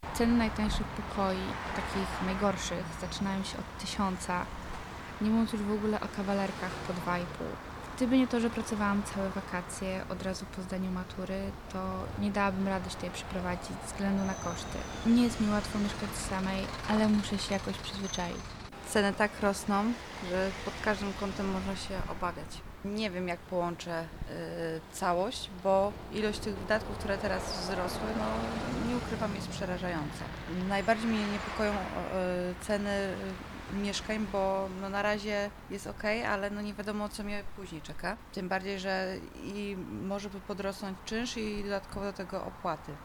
To część z myśli jakimi podzielili się słuchacze wrocławskich uczelni wyższych, z którymi rozmawialiśmy.